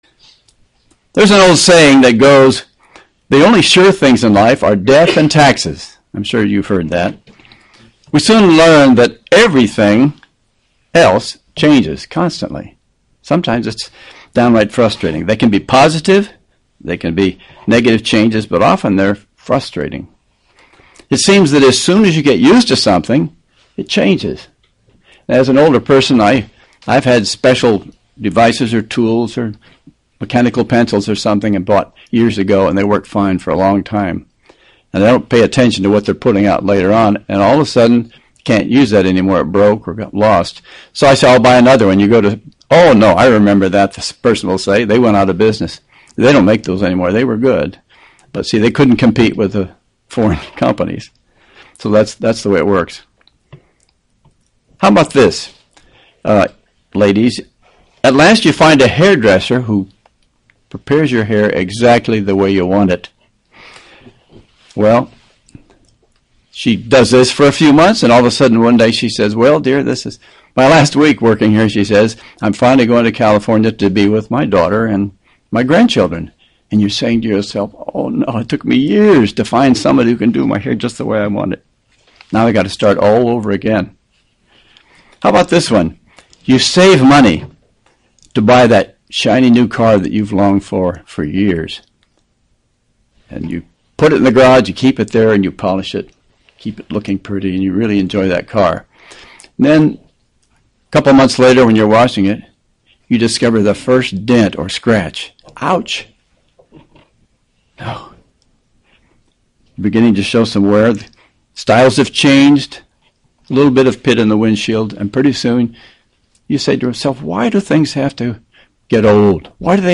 UCG Sermon Studying the bible?
Given in Buford, GA